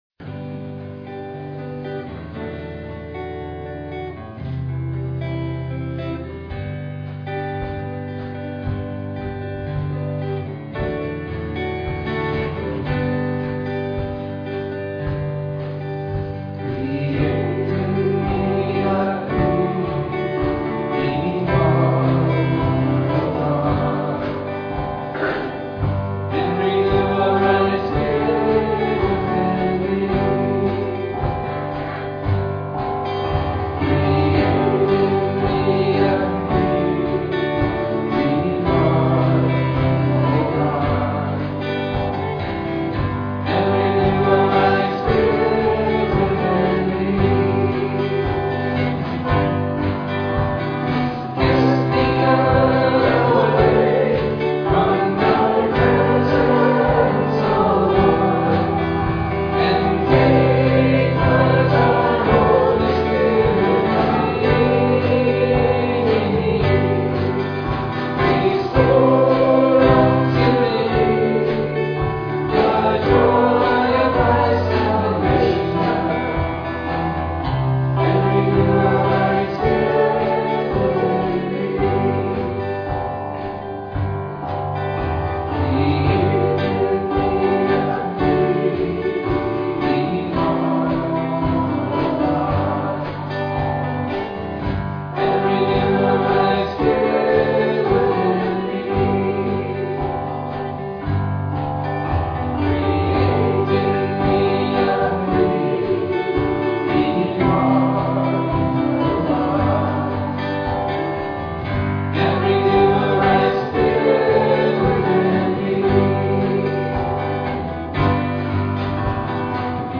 Worship
We do predominately more contemporary-style music with more traditional hymns done from time to time.